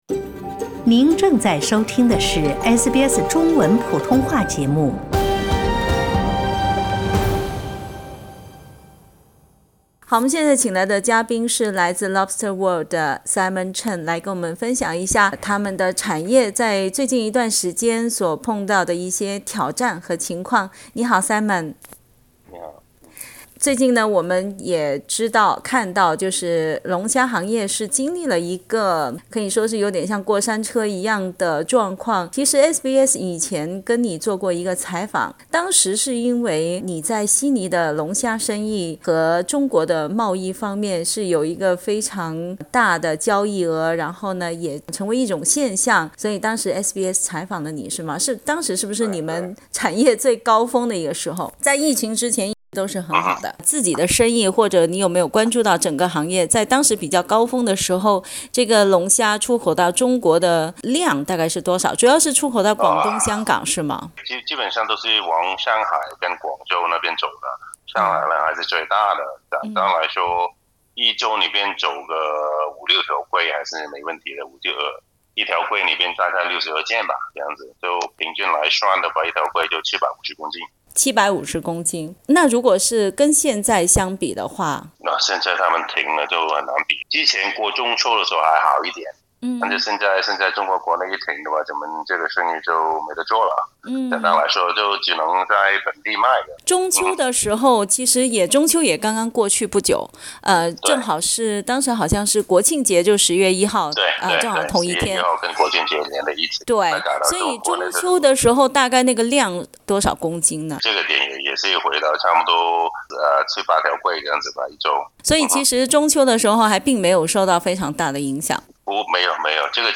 （请听采访） 澳大利亚人必须与他人保持至少 1.5米的社交距离，请查看您所在州或领地的最新社交限制措施 。